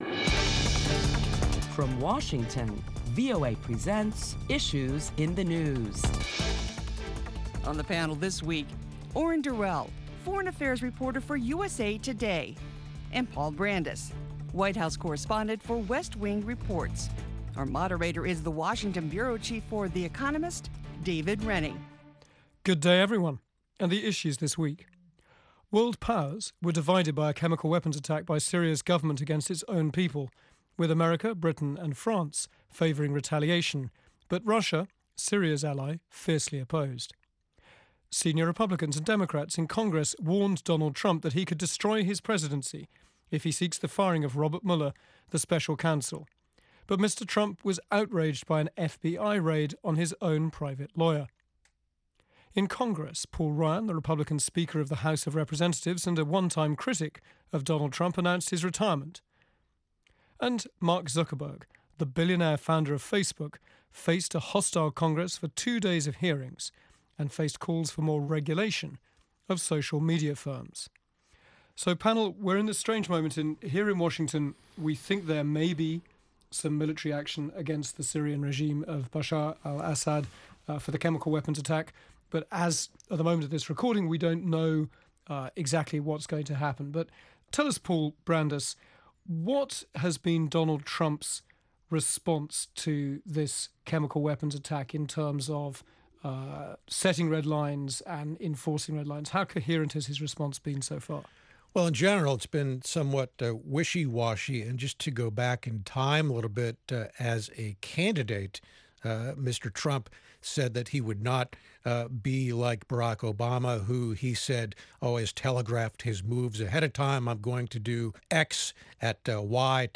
Top Washington journalists talk about the week’s headlines on Issues in the News including the suspected chemical attack on a Syrian rebel stronghold.